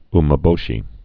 (mə-bōshē)